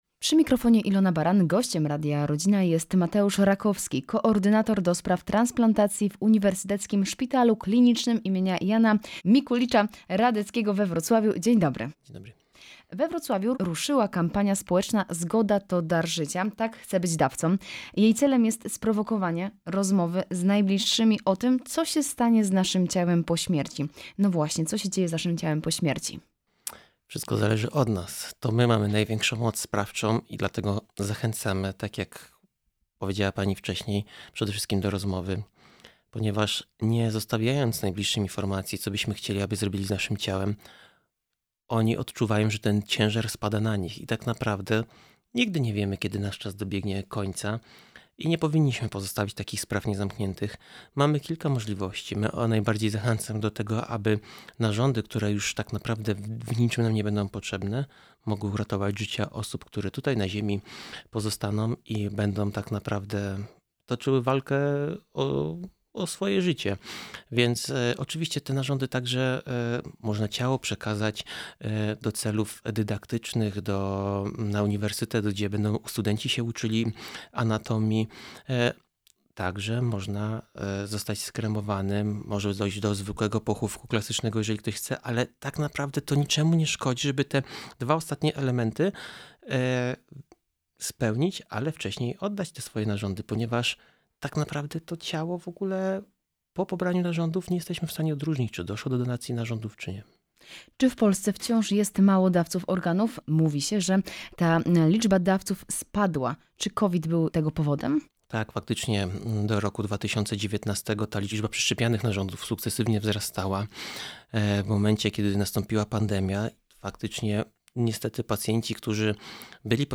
01-rozmowa-zgoda-to-dar-zycia-tak-chce-byc-dawca.mp3